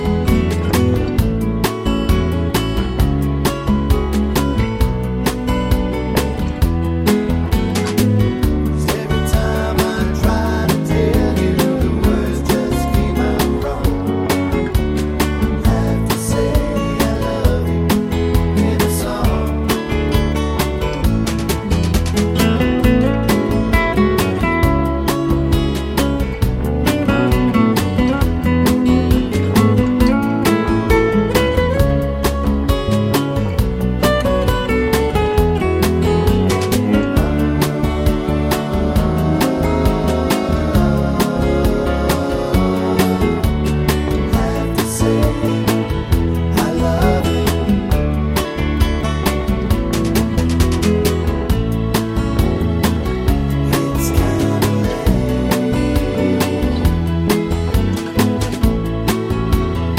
no Backing Vocals Country (Male) 2:32 Buy £1.50